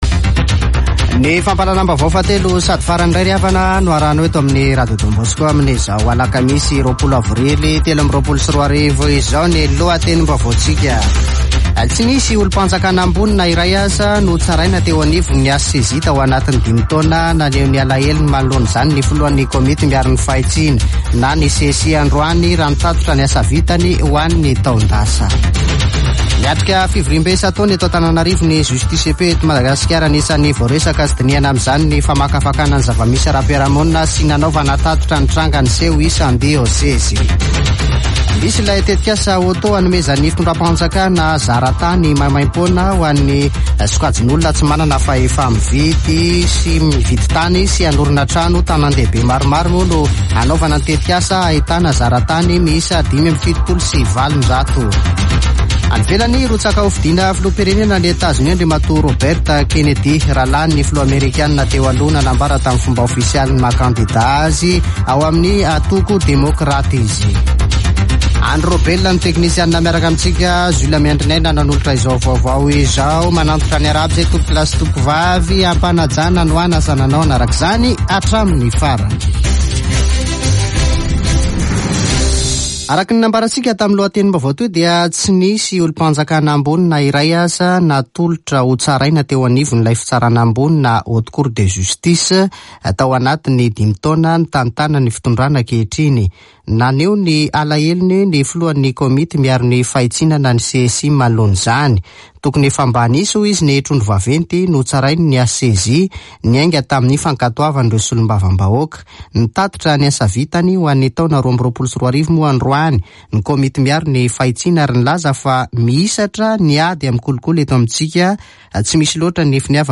[Vaovao hariva] Alakamisy 20 aprily 2023